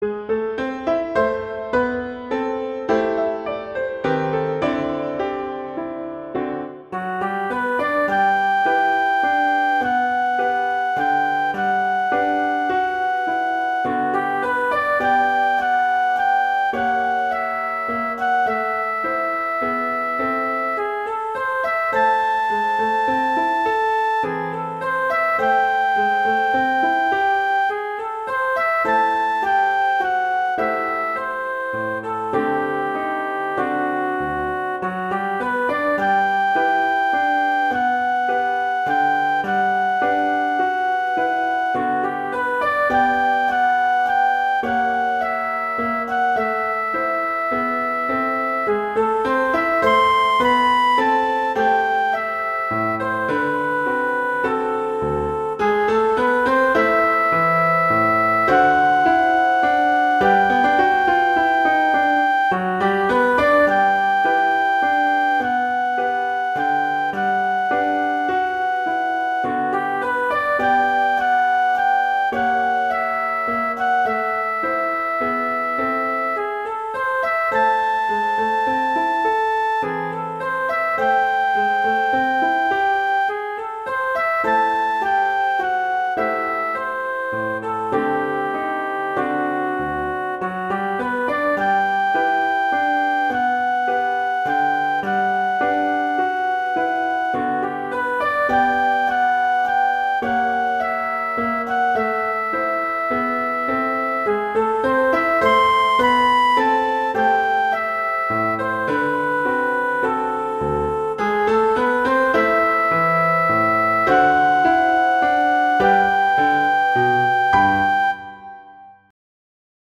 Instrumentation: flute & piano
jazz, traditional, wedding, standards, festival, love
G major
♩=100 BPM